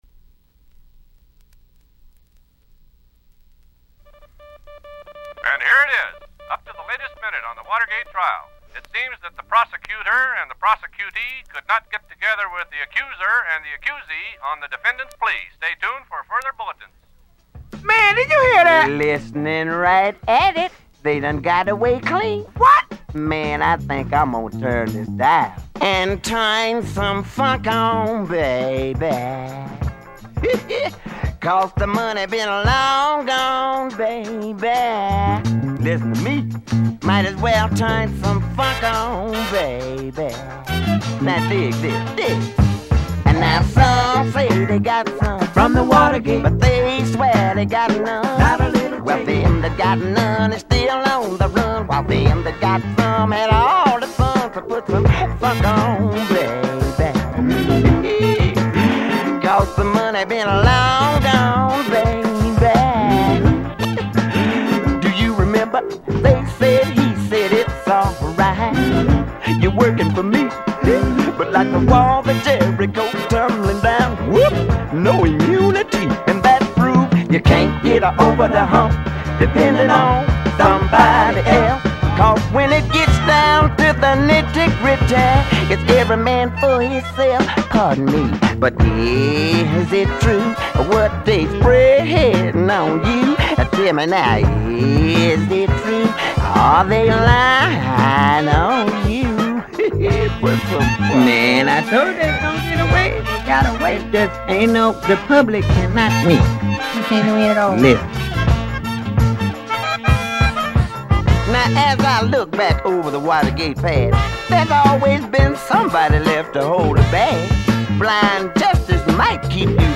Unknown accomp.
Hollywood, 1975  (prob two diff sessions)